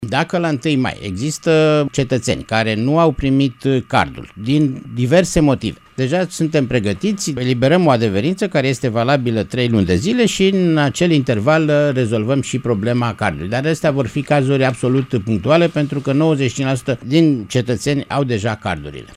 Așa a precizat, azi, preşedintele Casei Naţionale de Asigurări de Sănătate, Vasile Ciurchea, la Radio România Actualităţi: